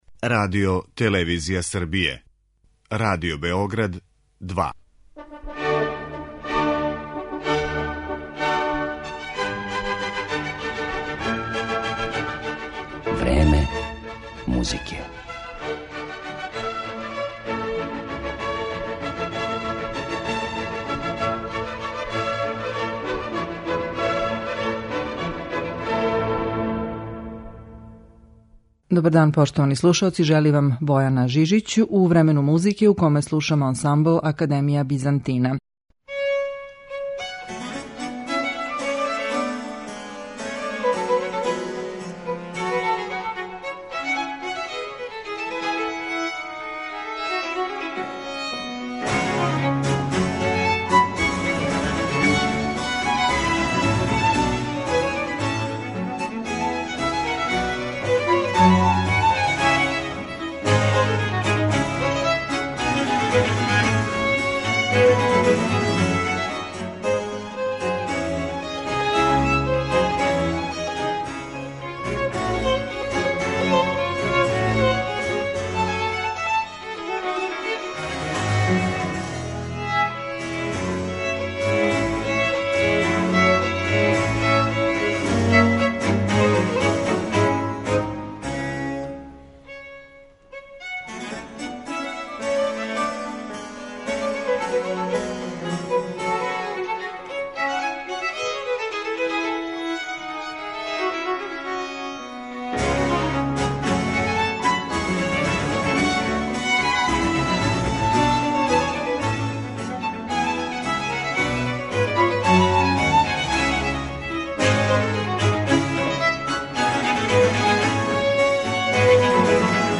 Изводиће дела италијанских барокних мајстора